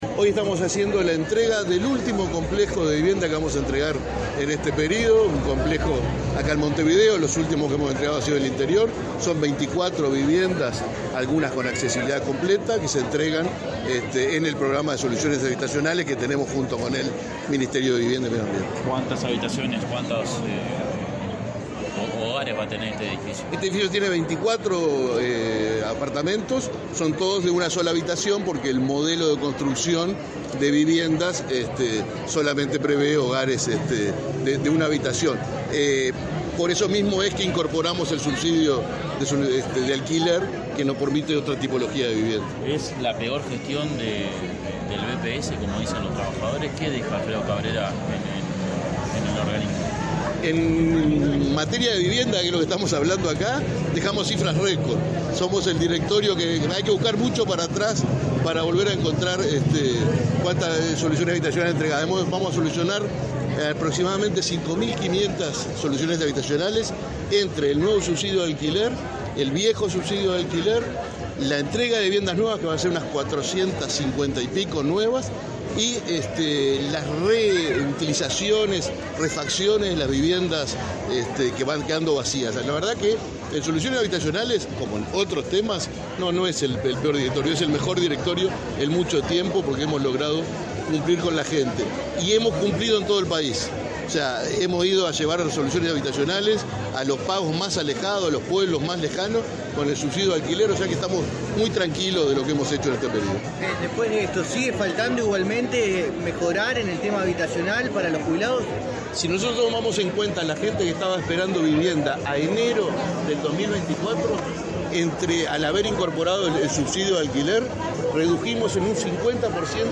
Declaraciones del presidente del BPS, Alfredo Cabrera
Declaraciones del presidente del BPS, Alfredo Cabrera 14/02/2025 Compartir Facebook X Copiar enlace WhatsApp LinkedIn Este 14 de febrero, el Ministerio de Vivienda y Ordenamiento Territorial (MVOT) entregó, en coordinación con el Banco de Previsión Social (BPS), viviendas para jubilados y pensionistas. Antes del evento, el presidente del BPS, Alfredo Cabrera, realizó declaraciones.